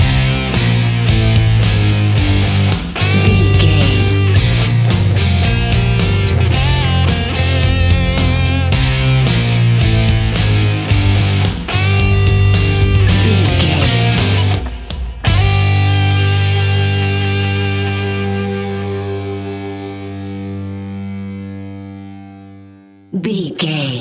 Epic / Action
Aeolian/Minor
hard rock
heavy rock
blues rock
instrumentals
Rock Bass
heavy drums
distorted guitars
hammond organ